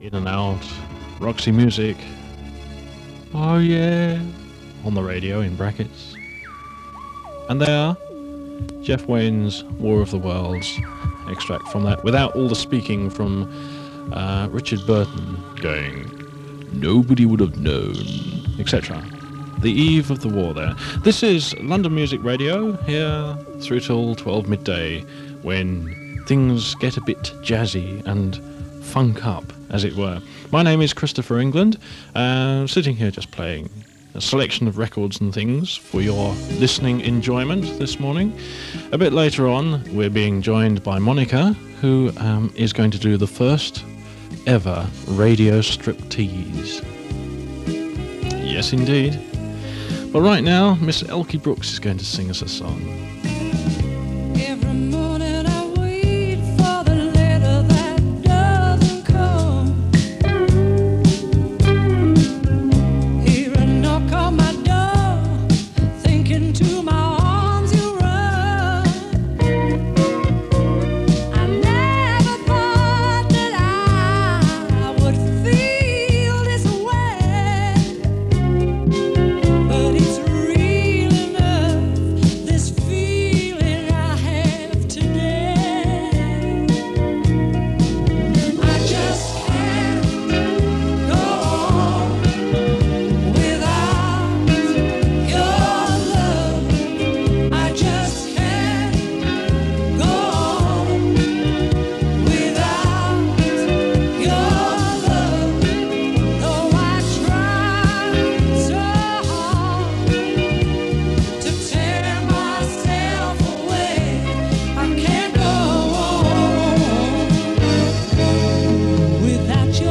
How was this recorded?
Recorded from 94.5MHz in mono in Ickenham. 62MB 55mins